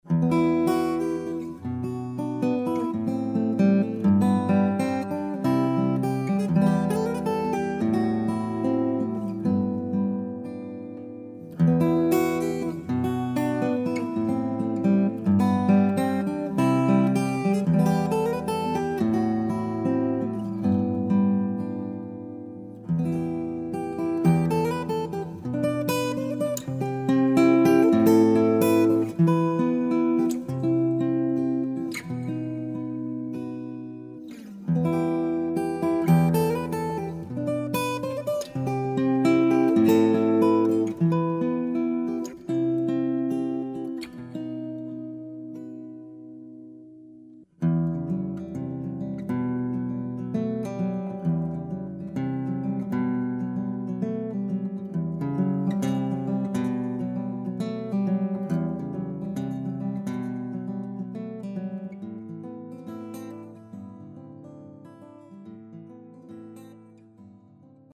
Guitare picking & solo, vocal, old-time banjo
harmonica
Bluegrass banjo
mandoline
Fiddle